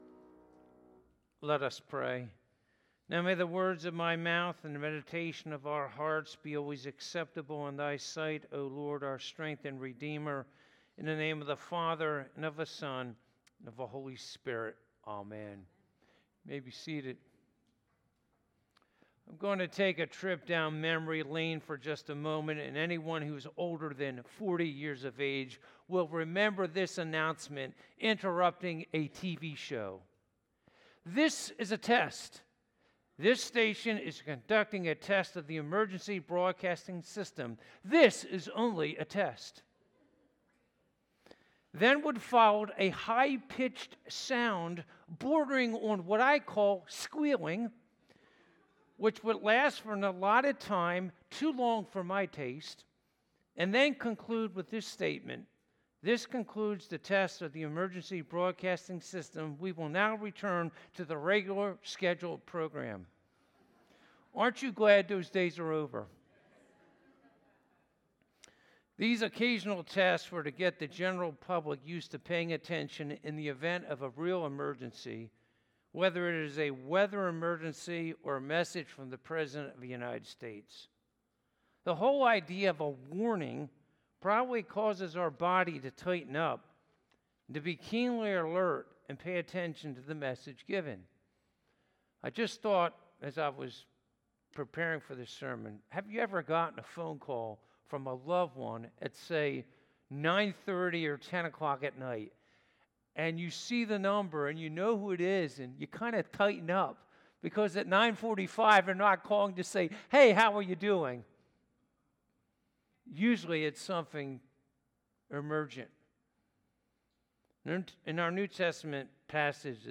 Passage: Luke 20:45 - 21:9 Service Type: Sunday Morning